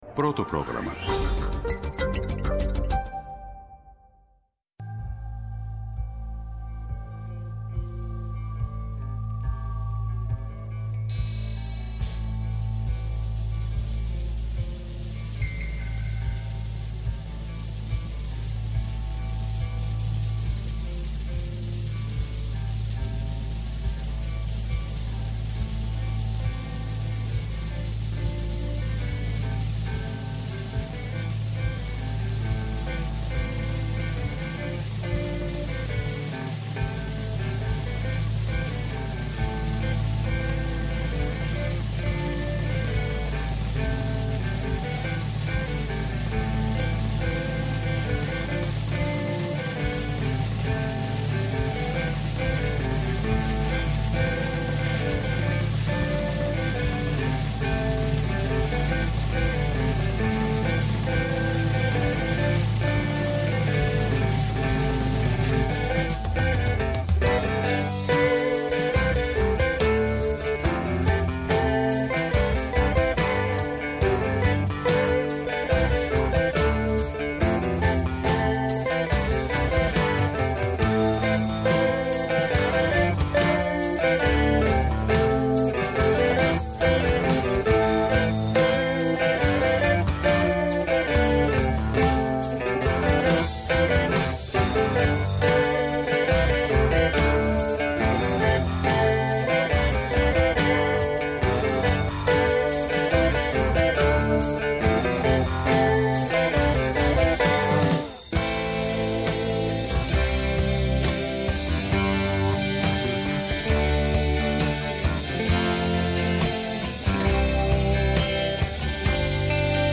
-O Πάρις Κουκουλόπουλος, βουλευτής ΠΑΣΟΚ.